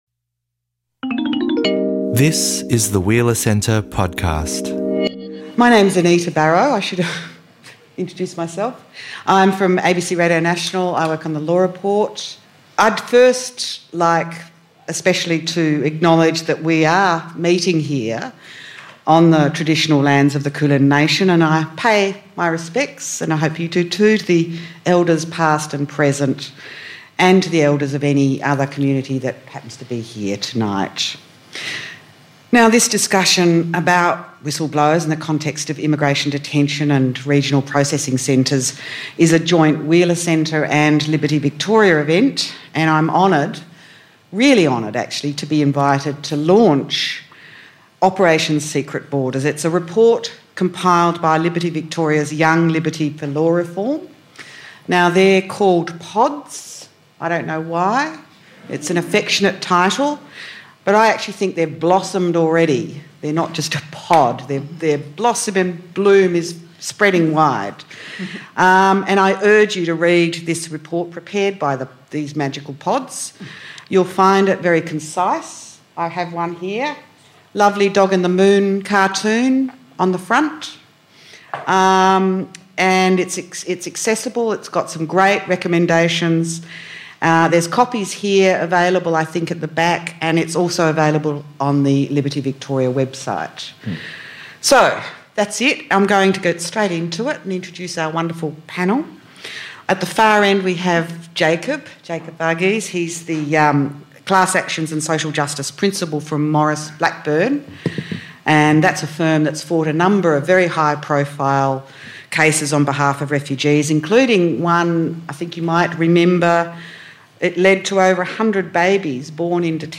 Coinciding with a report by Liberty Victoria's Young Liberty for Law Reform into government transparency and border protection policy, our panel will explore the legal and cultural barriers to whistleblowing.